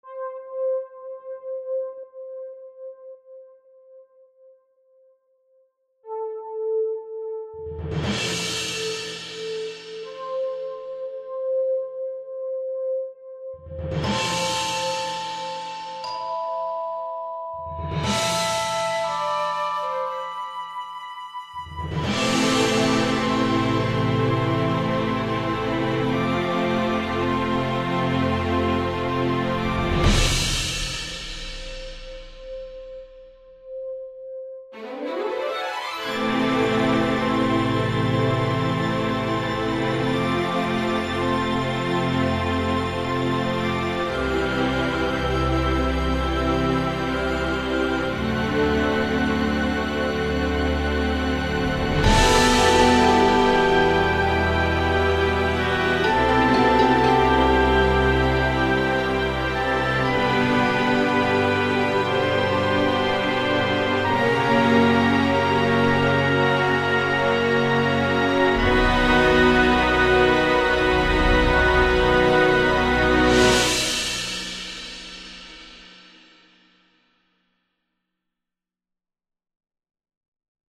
7 coups de cymbales? un rapport?
Son assez bien travaillé
une petite évolution sur le long du morceau, insertion d'instruments au fur et a mesure que le temps avance... mais un peu linéaire